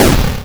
ihob/Assets/Extensions/explosionsoundslite/sounds/bakuhatu71.wav at master
bakuhatu71.wav